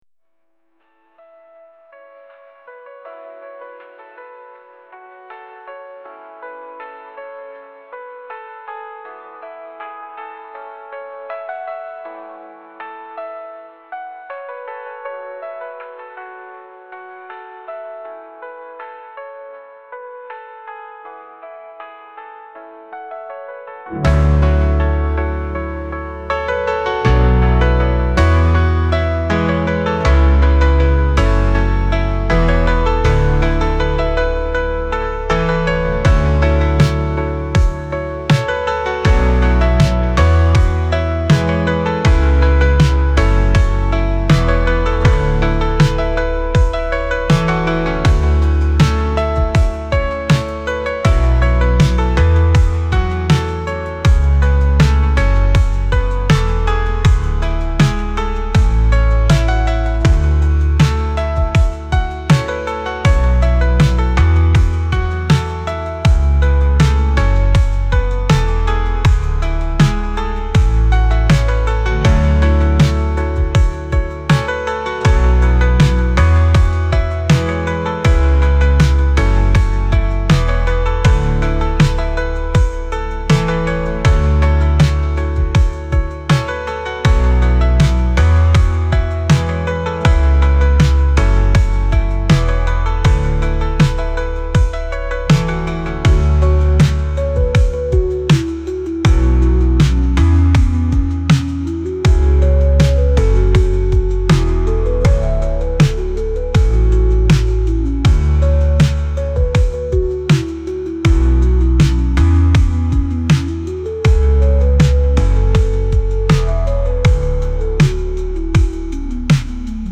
アンビエンス 懐かしい
雨